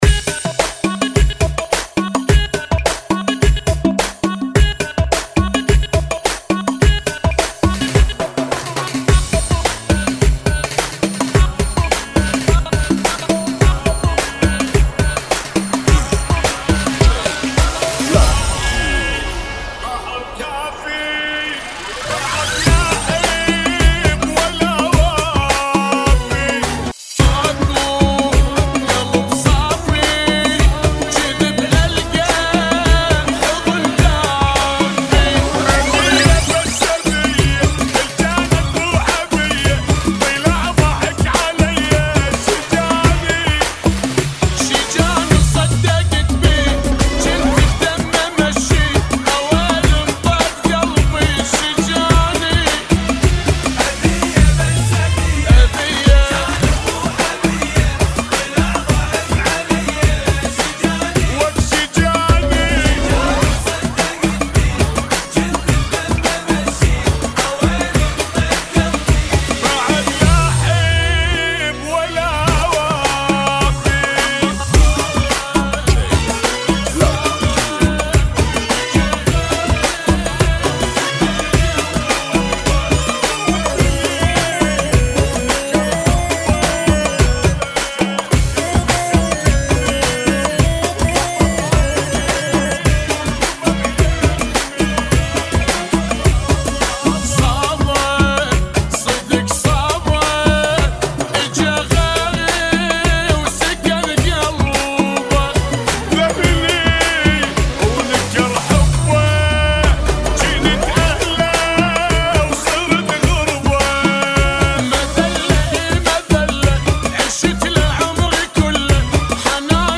[ 106 Bpm ]